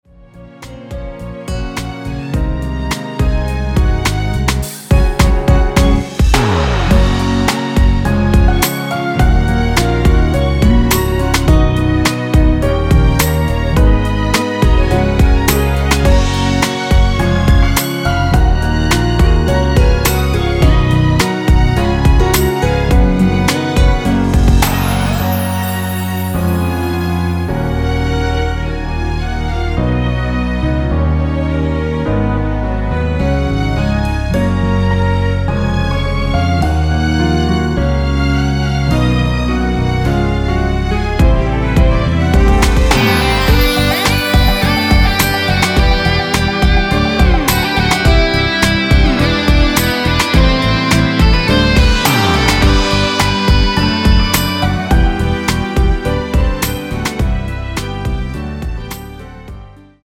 원키에서(-2)내린 (1절+후렴)으로 진행되는 MR입니다.
F#
◈ 곡명 옆 (-1)은 반음 내림, (+1)은 반음 올림 입니다.
앞부분30초, 뒷부분30초씩 편집해서 올려 드리고 있습니다.
중간에 음이 끈어지고 다시 나오는 이유는